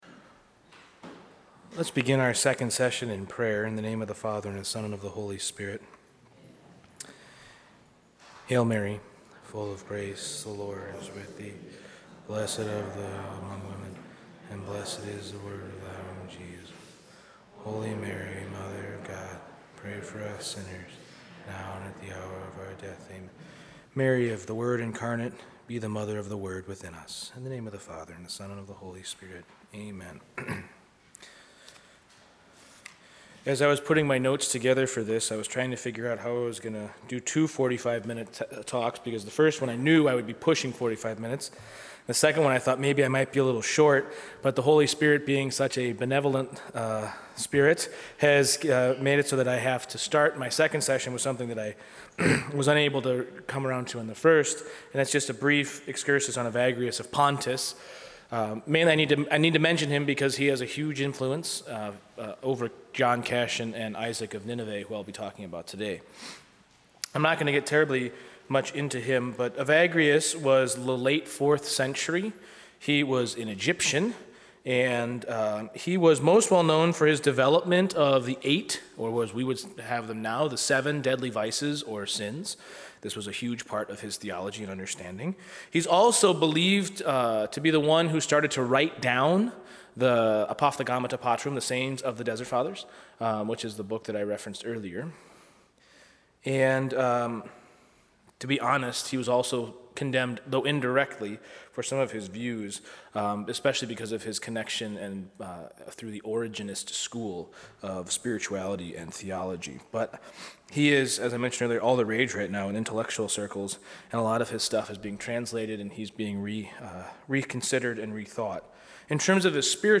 Purity of Heart & Prayer – Cassian & Isaac – Advent Reflection